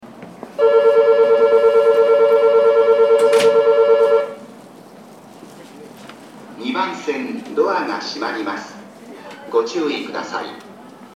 千駄ヶ谷駅　Sendagaya Station ◆スピーカー：ユニペックス箱型
2番線発車ベル
sendagaya2ban.mp3